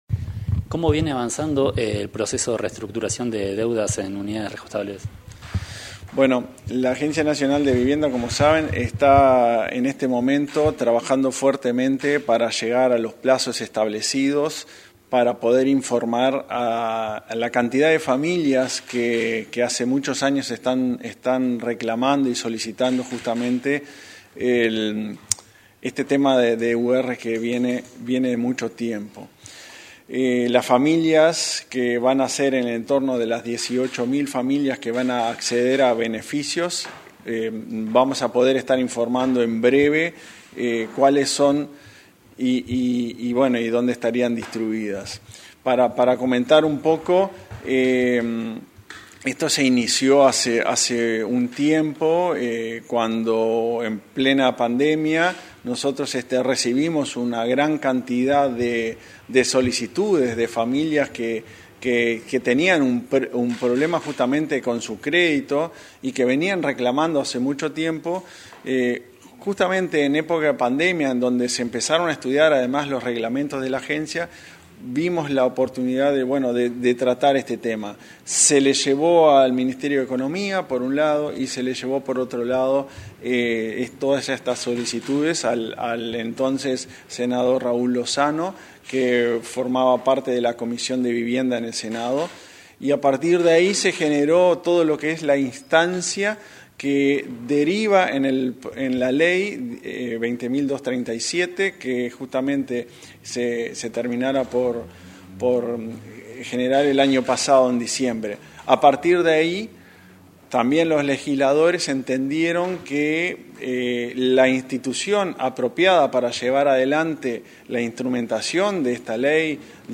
Entrevista al presidente de la Agencia Nacional de Vivienda, Klaus Mill